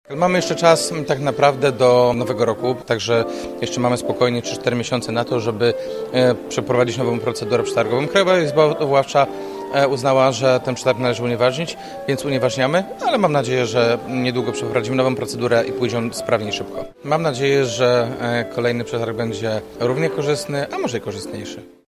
MG-6 ma jeszcze czas na rozpisanie i rozstrzygnięcie kolejnego przetargu – dodaje prezydent Wójcicki: